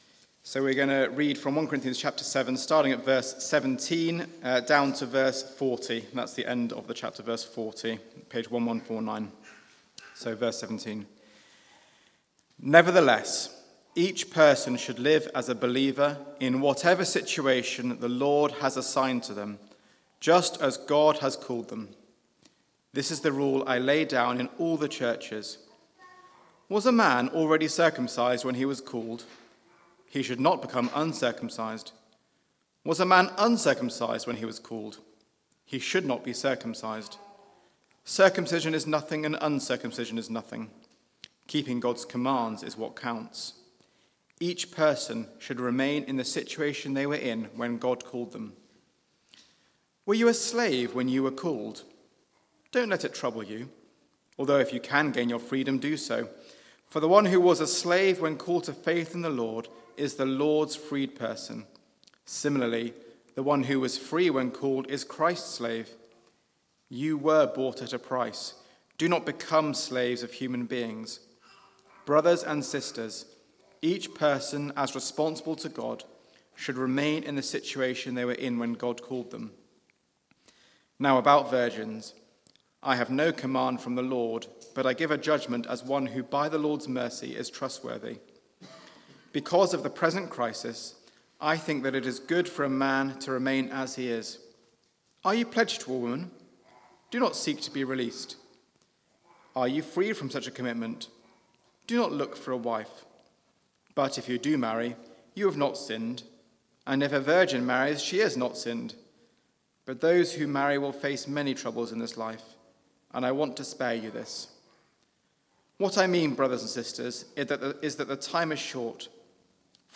Media for Church at the Green Sunday 4pm
Theme: Sermon